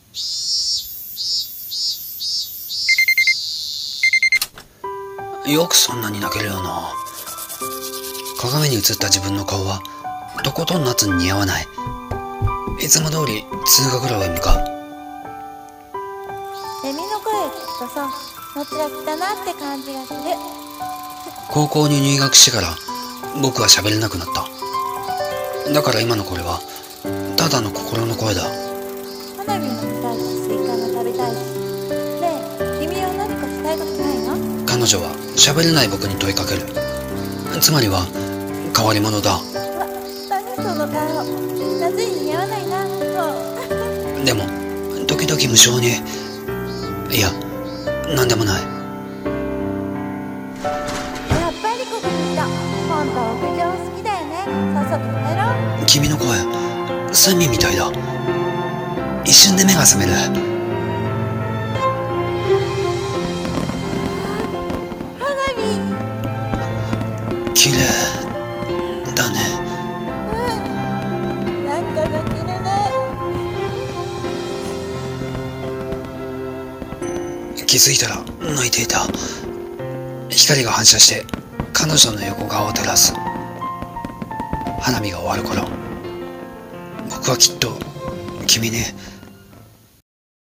【声劇】夏に鳴いたのは、